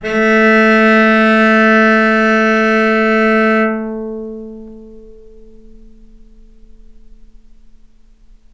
healing-soundscapes/Sound Banks/HSS_OP_Pack/Strings/cello/ord/vc-A3-mf.AIF at cc6ab30615e60d4e43e538d957f445ea33b7fdfc
vc-A3-mf.AIF